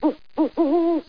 00063_Sound_owl.mp3